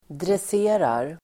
Uttal: [dres'e:rar]